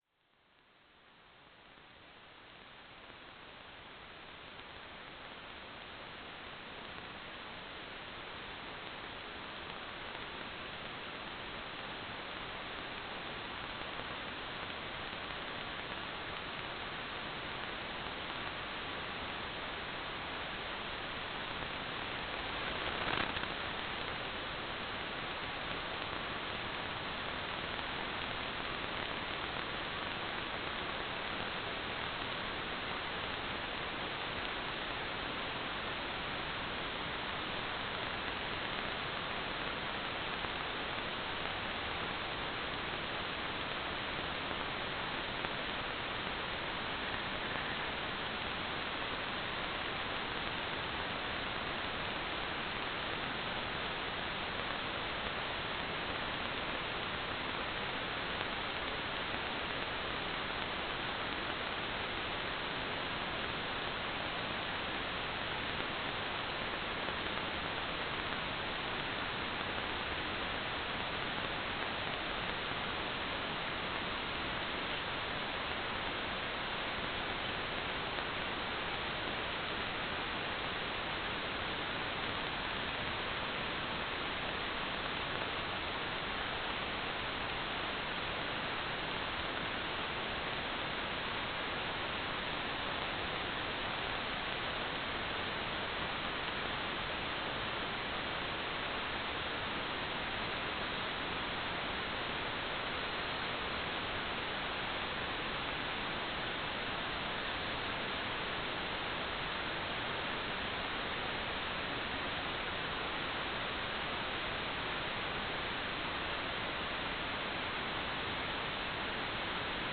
"transmitter_description": "CW",